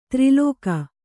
♪ tri lōka